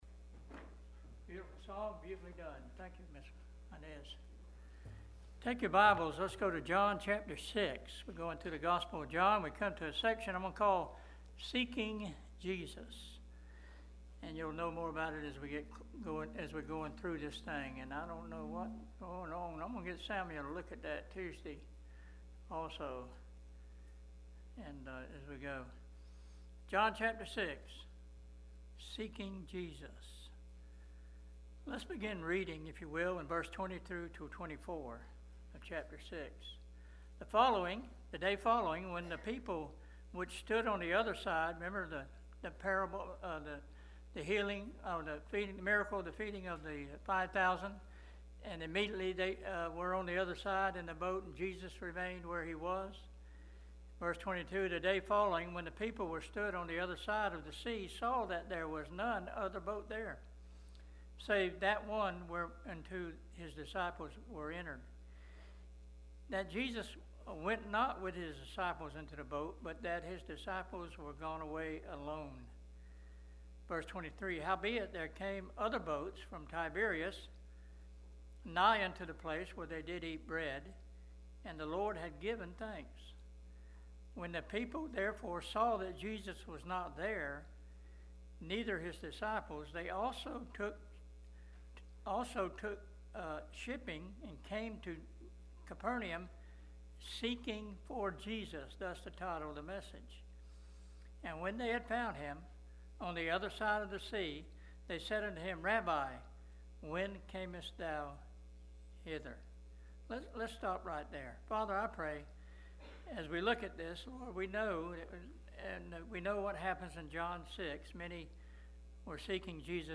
AM Sermon Outline: I. Seek Jesus for the Eternal, not the Temporal (John 6:26-27) II. Seek Jesus by Faith, not by Works (John 6:28-29) PLEASE CLICK THE PLAY BUTTON BELOW FOR AUDIO https